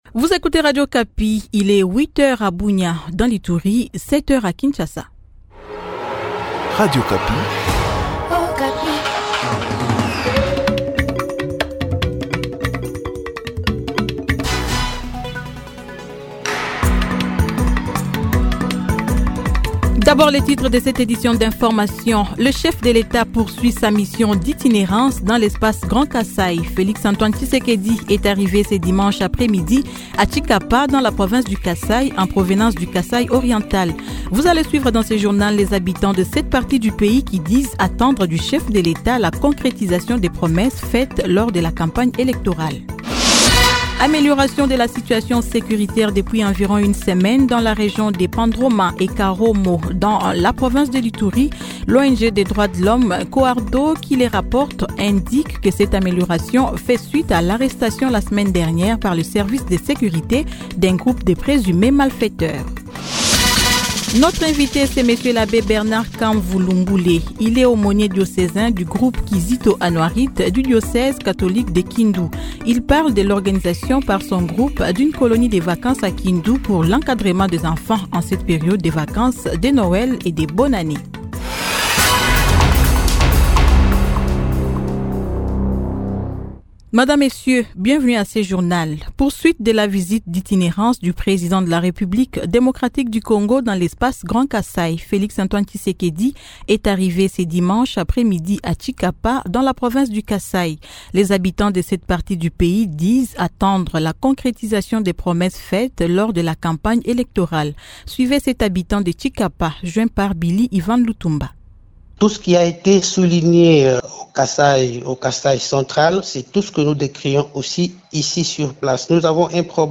Journal matin 07H-08H